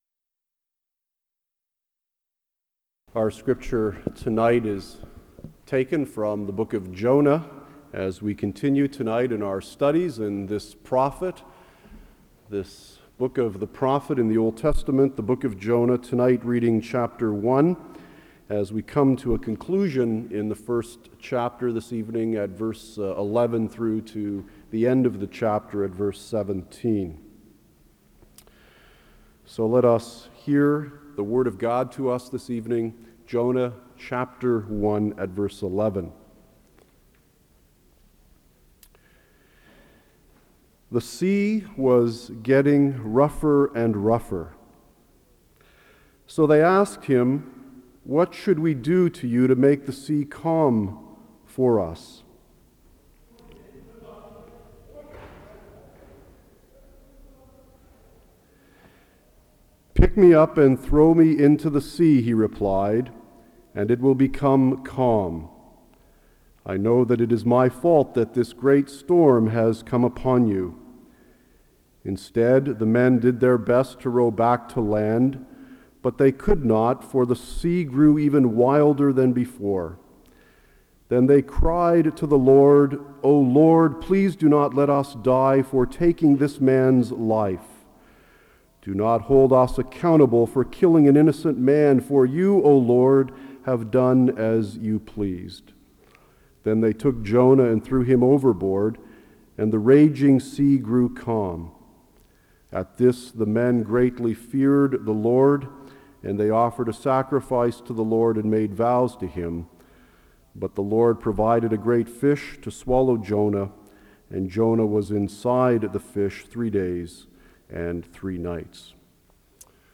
The sermon highlights the grace of God, which pursues Jonah even in his rebellion, culminating in his miraculous preservation by the great fish.